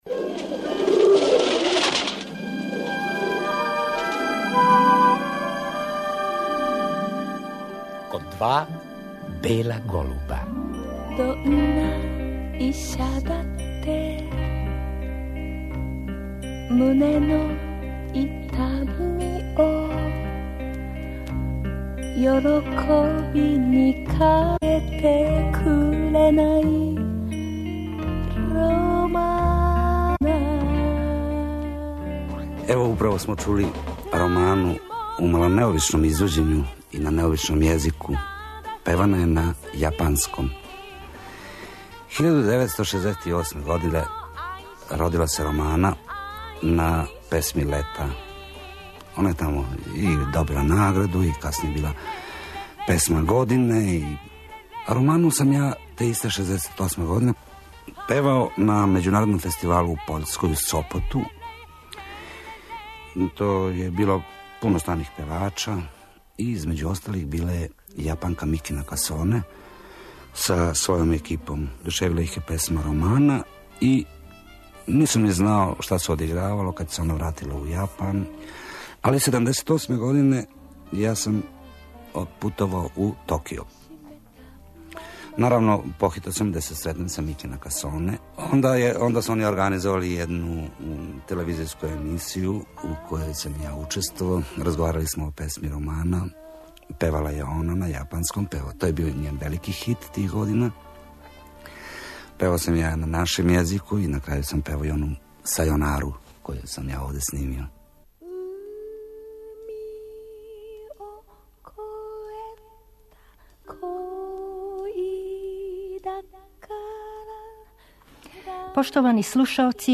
Поводом 81. рођендана Ђорђа Марјановића, у вечерашњој емисији слушаћемо сећања популарног певача снимљена осамдесетих година прошлог века.